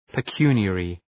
Προφορά
{pı’kju:nı,erı}